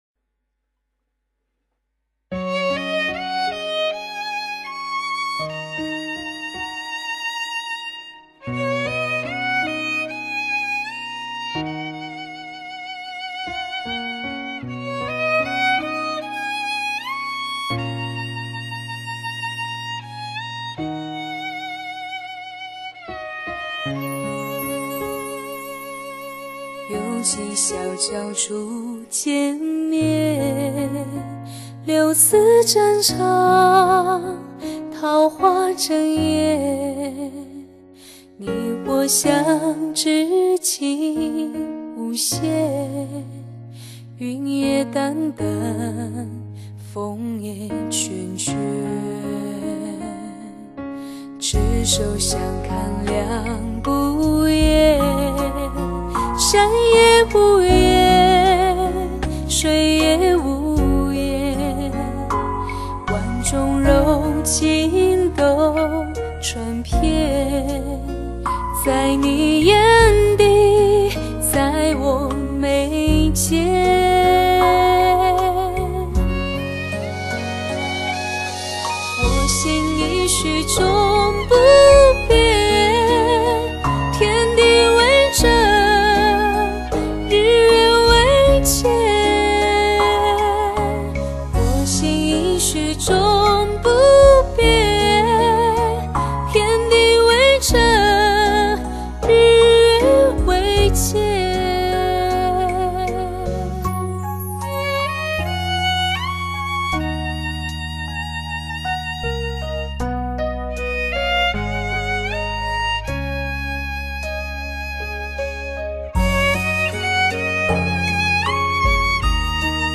青色烟雨 艳如牡丹, 宛如彩虹色彩的魔力女声,
赋予歌曲全新生命的灵魂演唱, 在岁月中风化相思 在歌声里漂淡离愁